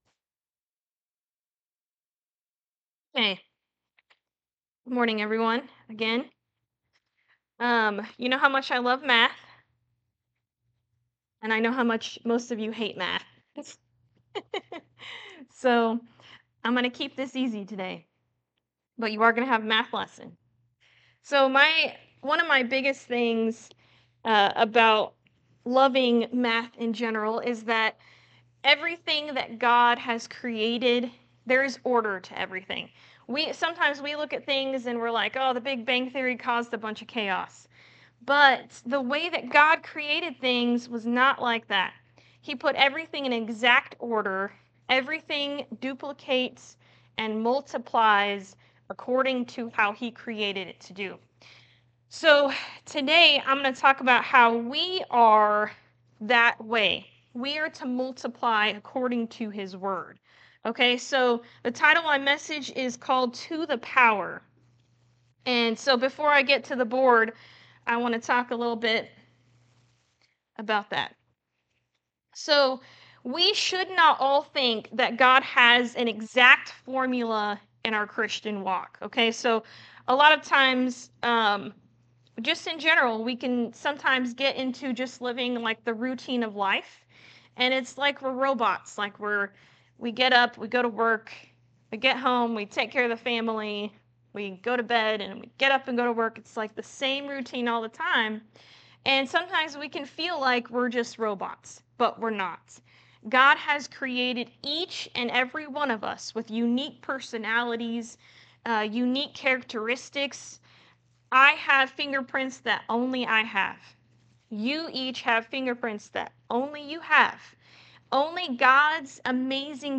Matthew 25:14-30 Service Type: Sunday Morning Service With God there are no variables
Sunday-Sermon-for-November-9-2025.mp3